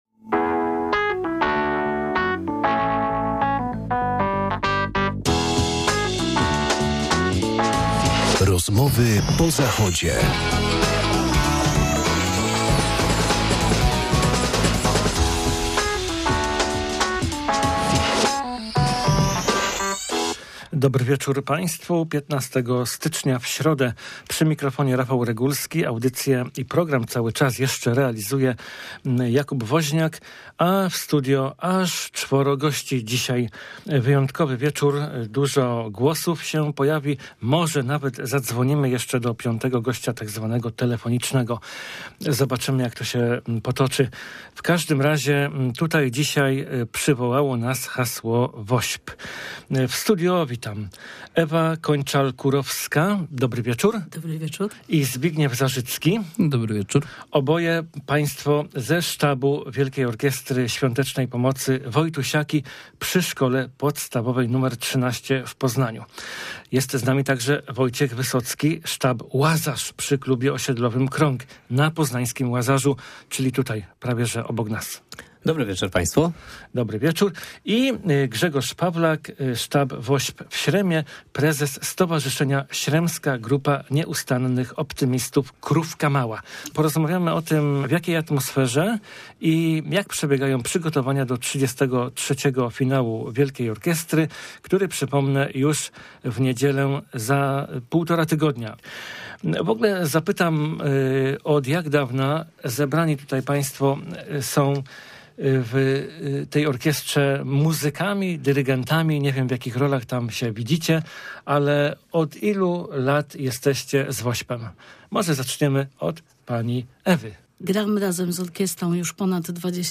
W środę wieczorem nasze studio odwiedzili „weterani” WOŚP, ludzie od lat związani z kolejnymi finałami Orkiestry. Opowiedzieli nam, jak to się zaczęło i dlaczego trwa mimo chwil zmęczenia, mimo niechęci różnych osób i mimo… upływającego czasu.